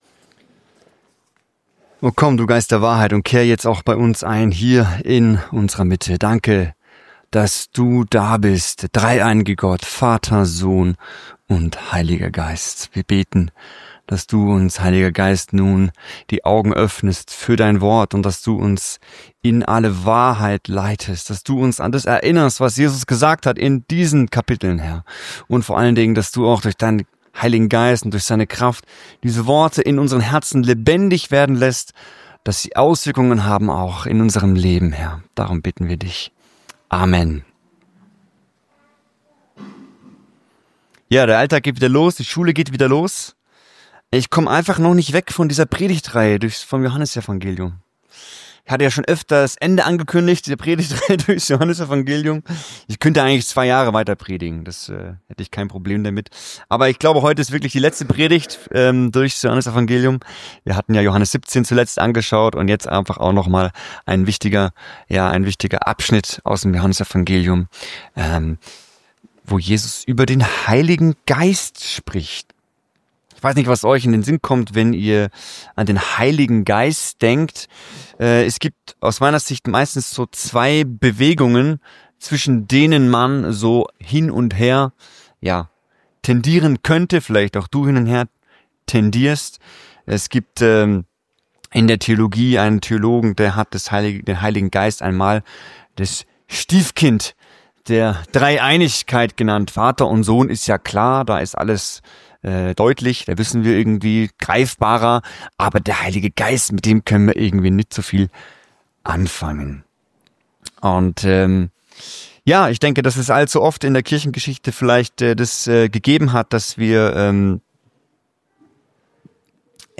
Mit diesem Podcast hörst Du die aktuellen Predigten.Wir sind eine kleine, evangelische Gemeinde (=Kirche) auf der schwäbischen Alb.